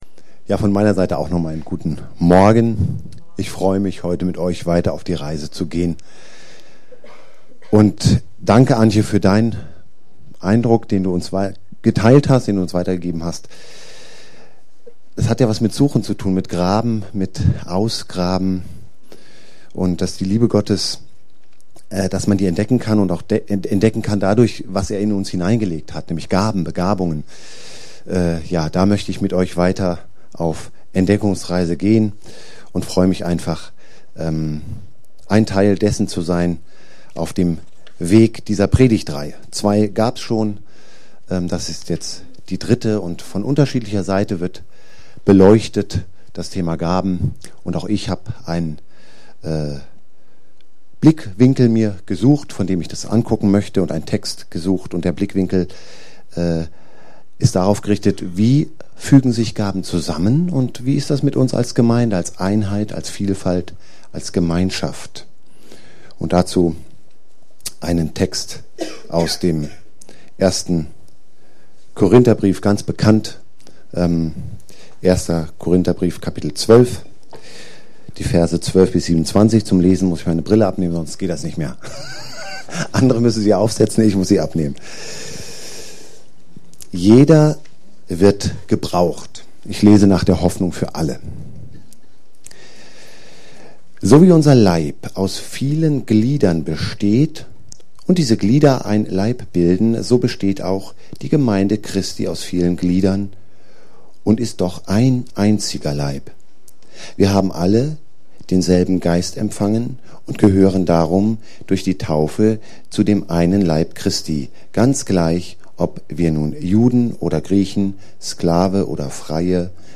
Geistliche Gaben Gottesdienst: Sonntag %todo_render% « Geistliche Gaben #2